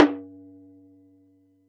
TM88 AfricaPerc.wav